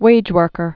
(wājwûrkər)